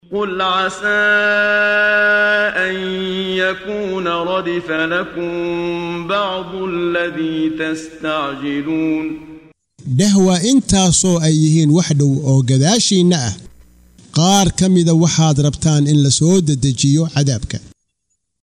Waa Akhrin Codeed Af Soomaali ah ee Macaanida Suuradda An-Namal ( Quraanjada ) oo u kala Qaybsan Aayado ahaan ayna la Socoto Akhrinta Qaariga Sheekh Muxammad Siddiiq Al-Manshaawi.